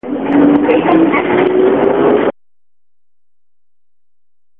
路線バス車両 音声合成装置 クラリオン(ディスプレイ) ドアが閉まった際に「発車しますのでご注意下さい」と流れます。